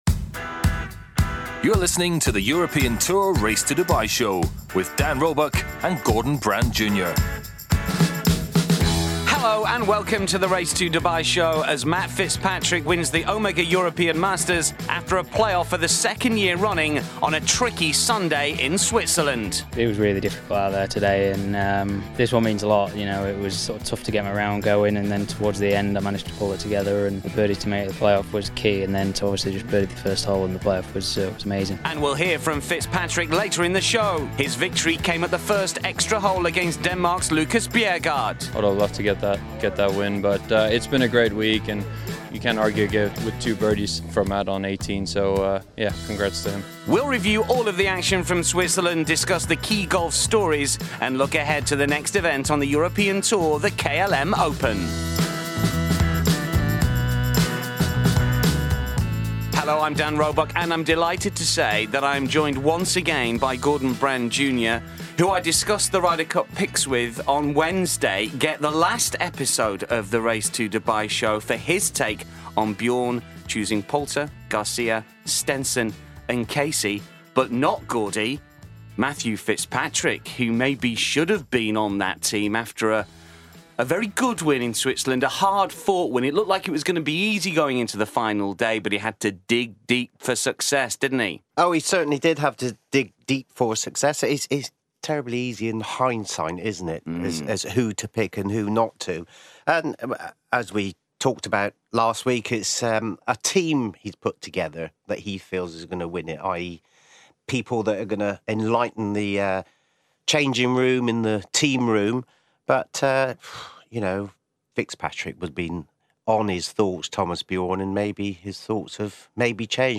We hear from both Fitzpatrick and the in-form Dane, as well Mike Lorenzo-Vera who was third in Switzerland.